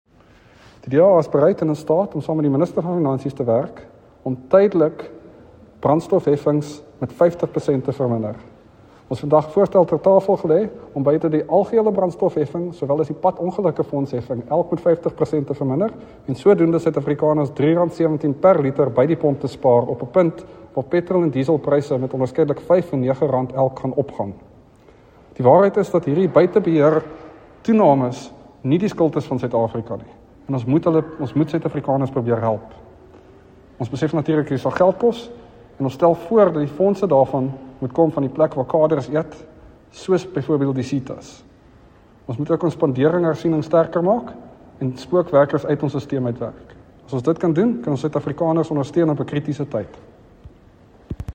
Afrikaans soundbites by Dr Mark Burke MP.